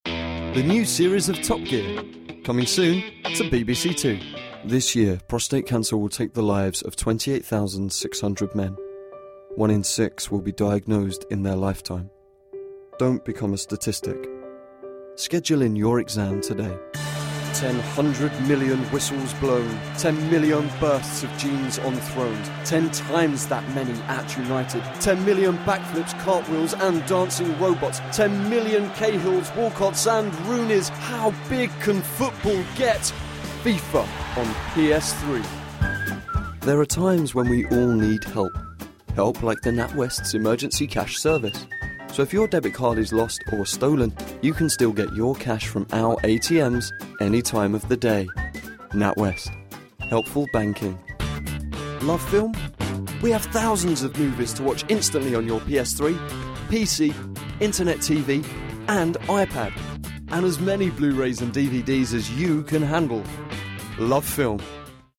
British Accents
Brisbane (Home Studio)
Male, 30s, native to UK – (Essex/Estuary English)